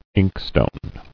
[ink·stone]